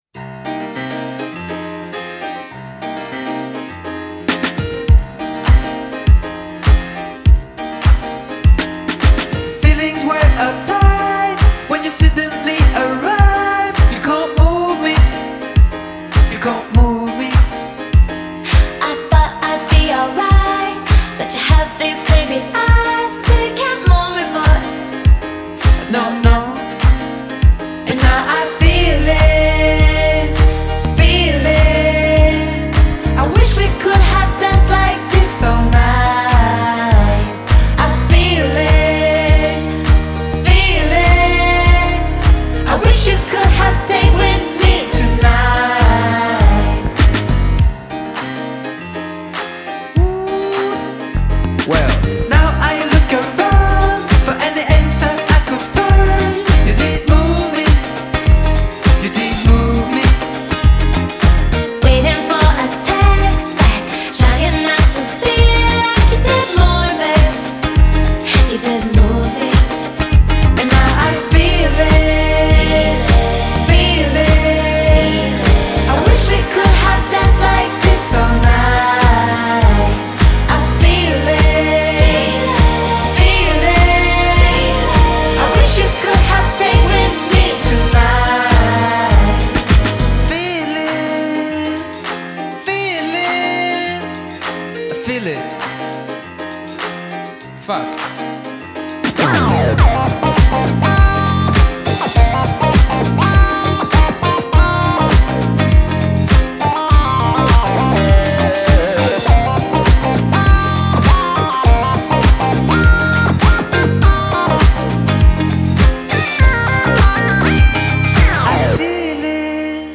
это яркая поп-композиция